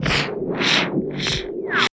81 SCI-FI -L.wav